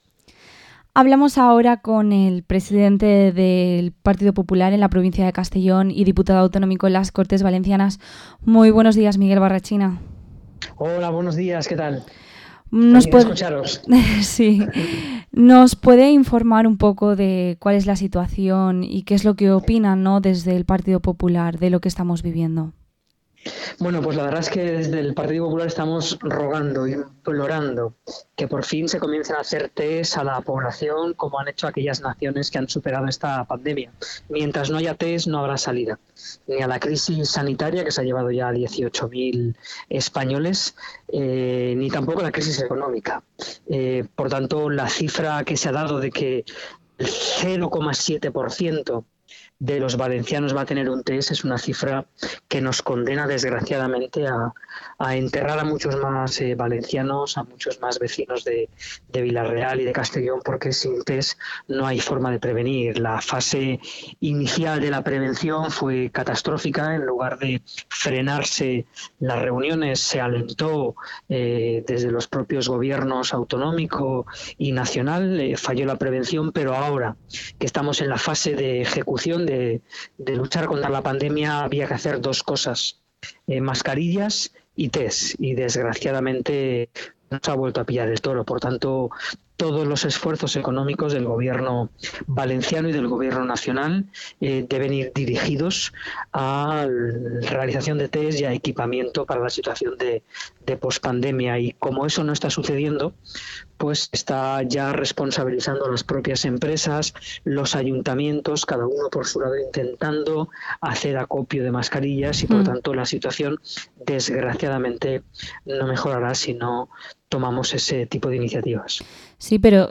Entrevista al diputado autonómico por el Partido Popular, Miguel Barrachina